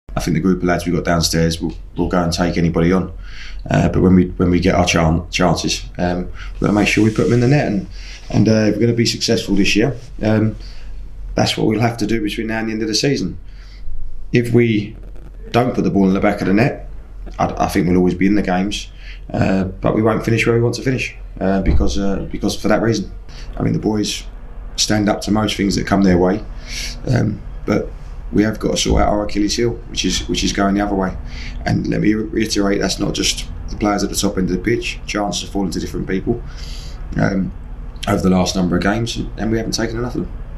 LISTEN: Gillingham's head coach Stephen Clemence spoke to reporters after their 1-all draw with Grimsby Town - 16/03/2024